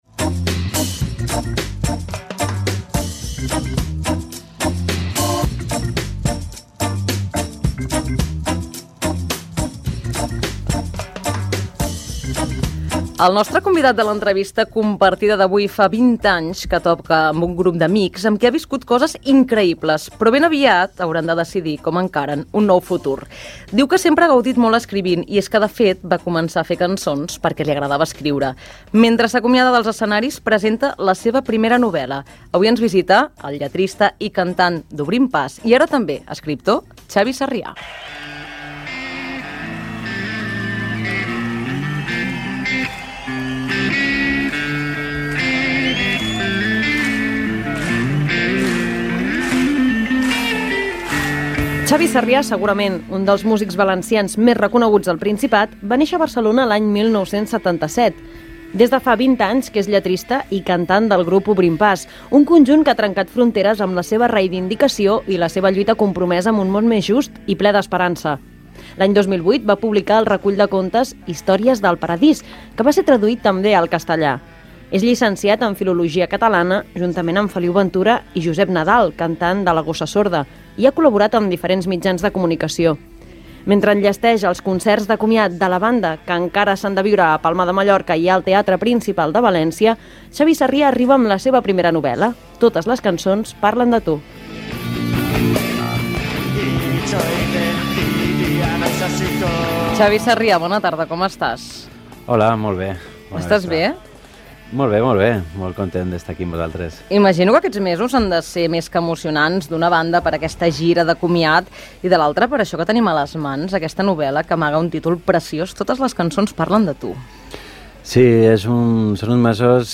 L'entrevista compartida: Xavi Sarrià - La Xarxa, 2014